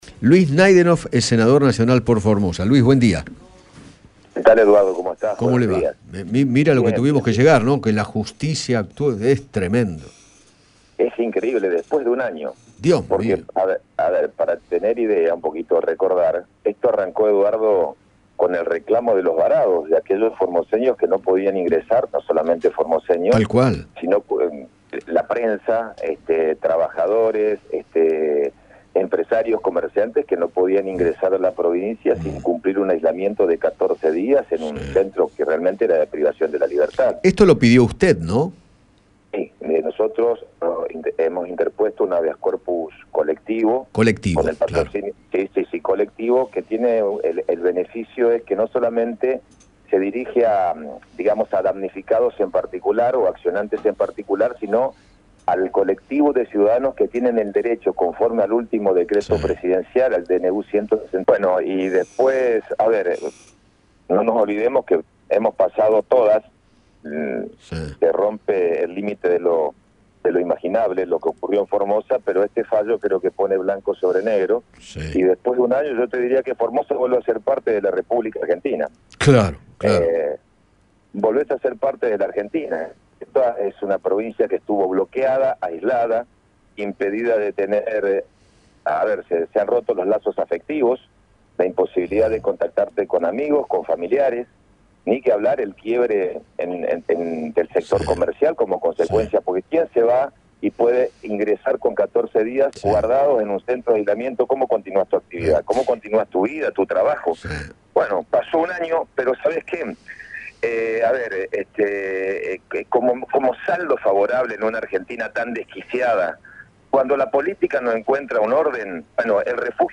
Luis Naidenoff, senador nacional por Formosa, dialogó con Eduardo Feinmann luego de que la Justicia inste a Gildo Insfrán a garantizar la libre circulación de las personas que tienen PCR negativo.